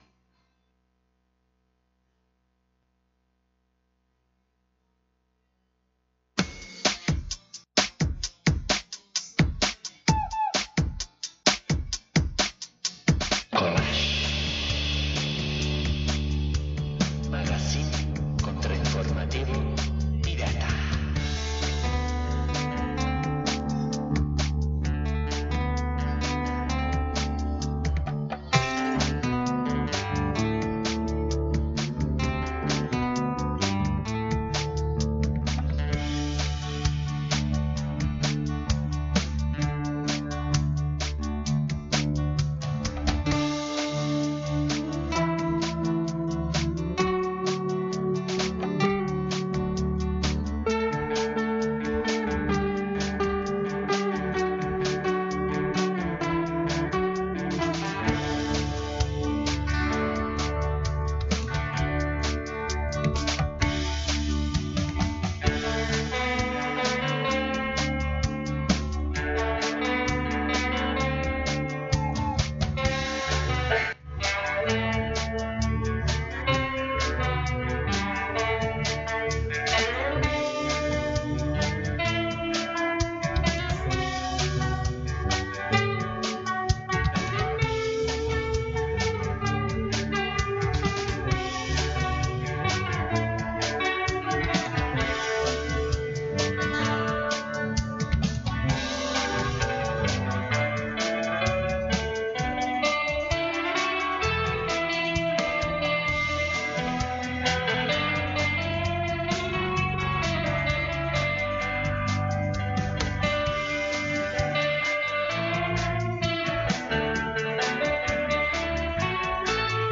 2005tik uhinetan dagoen irratsaio kontrainformatiboa. Elementu ezberdinez osatutako kolax radiofonikoak, gai sozialak, kulturalak… jorratzen ditu. Kolaxa sortzeko elkarrizketak, albisteak eta kolaboratzaile ezberdinen ahotsak tartekatzen ditu. 15ean behin ordubete.